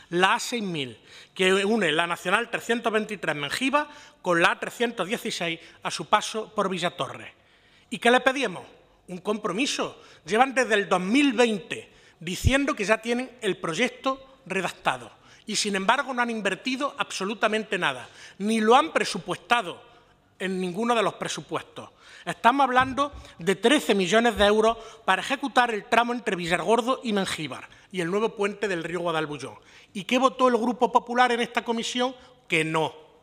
Comisión de Fomento
Cortes de sonido